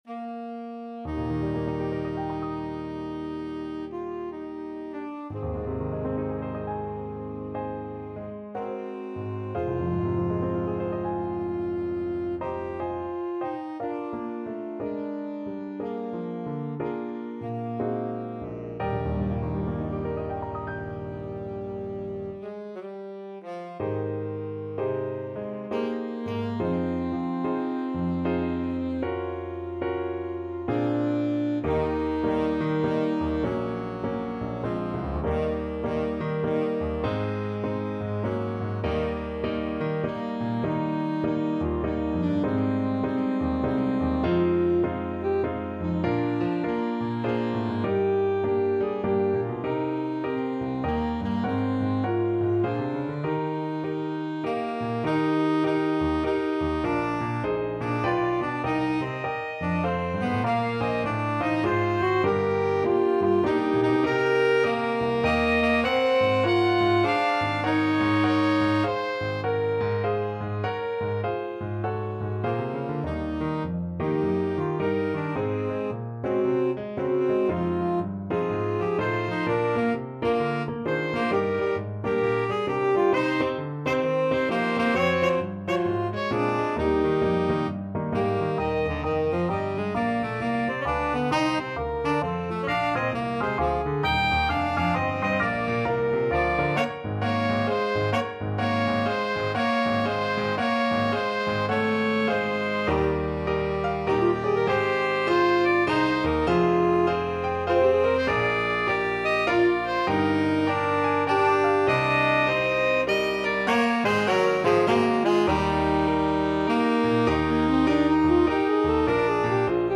Alto SaxophoneTenor Saxophone
Adagio (swung throughout) =c.60
Jazz (View more Jazz Alto-Tenor-Sax Duet Music)